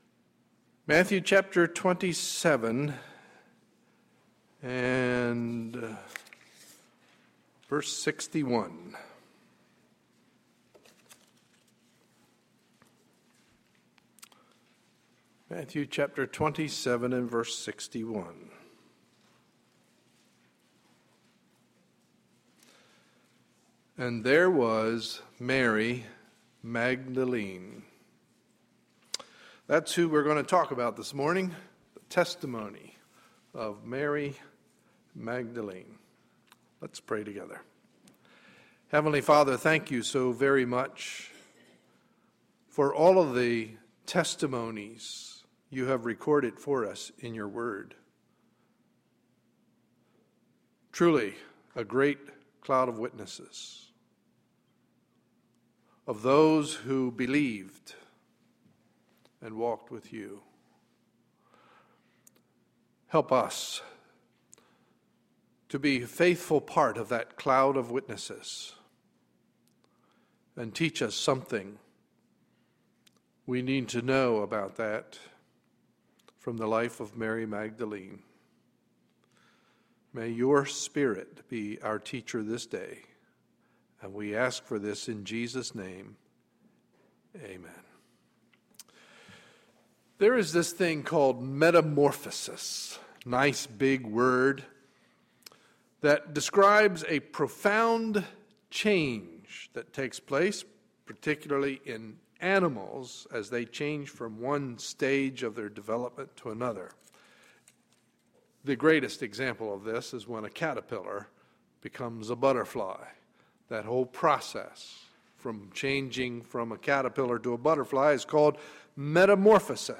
Sunday, September 2, 2012 – Morning Message